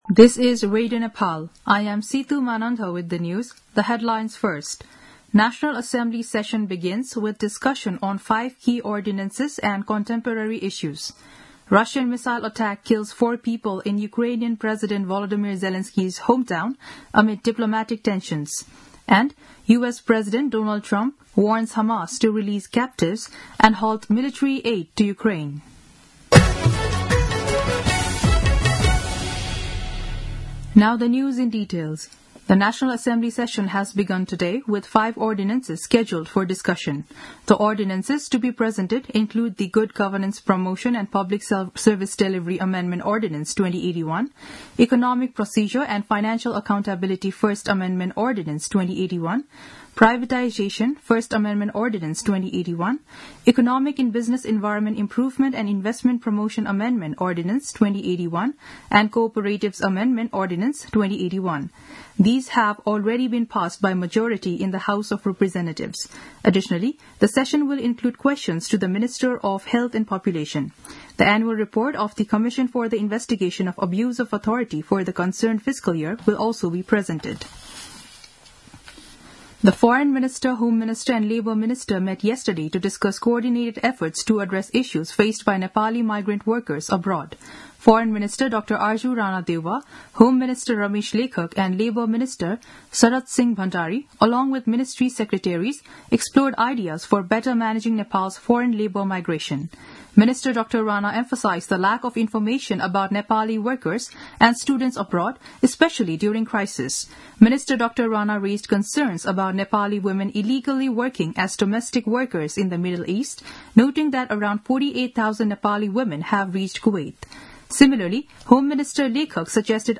दिउँसो २ बजेको अङ्ग्रेजी समाचार : २३ फागुन , २०८१
2-pm-english-news-.mp3